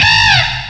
cry_not_archen.aif